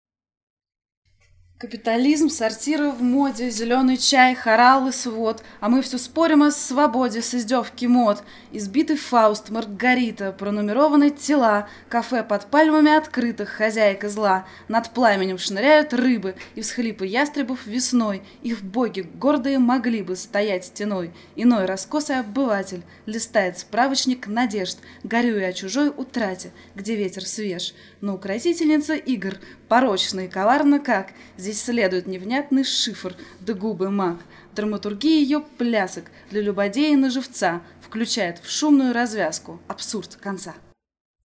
поэзия